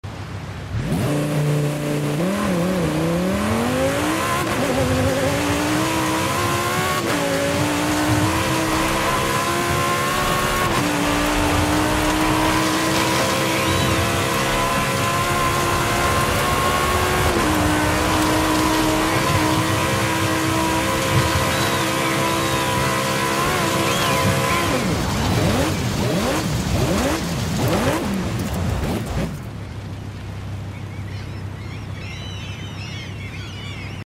2014 Lamborghini Huracan LP 610 4 Sound Effects Free Download